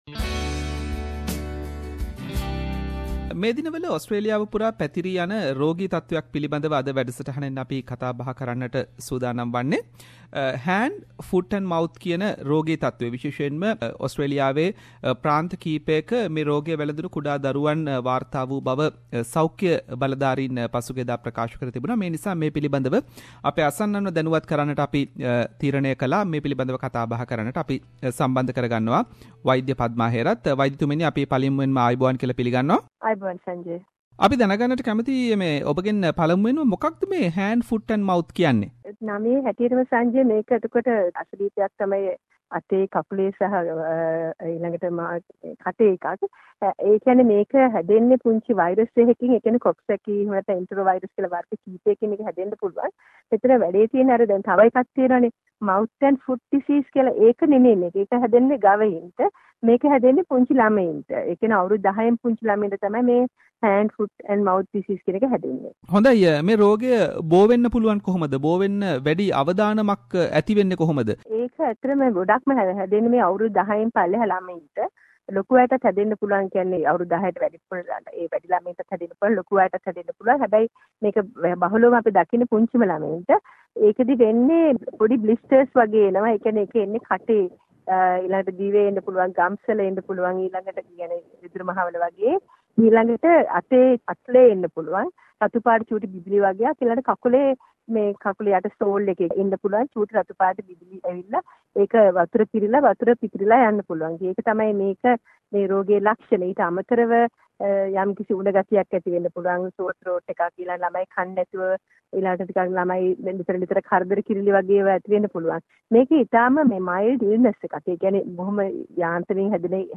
Special interview regarding Hand foot and mouth disease